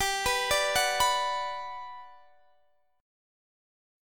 GM7 Chord (page 2)
Listen to GM7 strummed